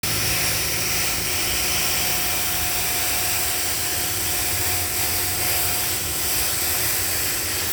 철공소에서 작업하는 소리였다.
서둘러 휴대전화의 녹음 버튼을 눌렀다. 조용히 돌을 놓고 가듯이 녹음 또한 티 나지 않게 진행해야 했기에 분량이 길지 않다.
* 문래동 작업장 소리 채집